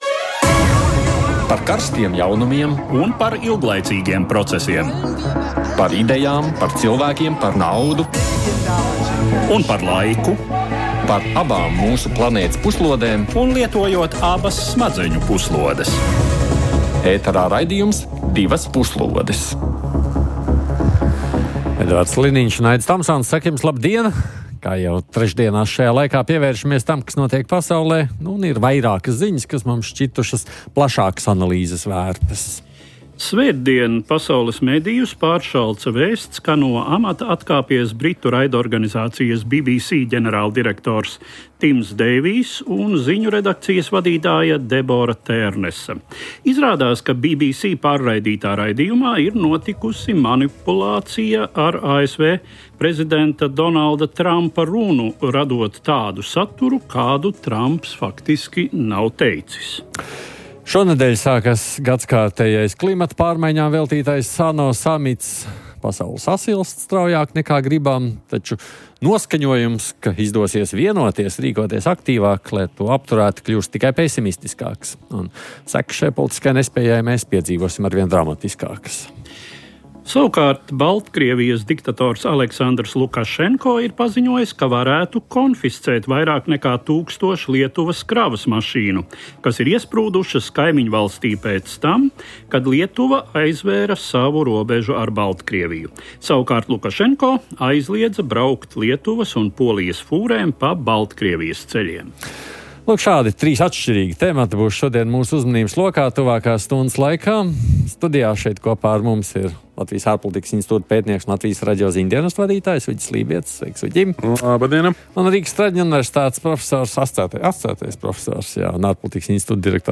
Raidsabiedrības BBC ģenerāldirektora un ziņu dienesta vadītājas demisijas apstākļi, ANO Klimata pārmaiņu konference Belenā (Brazīlijā) un globālās klimata politikas aktualitātes, kā arī Baltkrievijas diktatora Lukašenko jaunākie diplomātiskie manevri slēgtās Polijas un Lietuvas robežas sakarā. Ārpolitikas aktualitātes studijā pārrunājam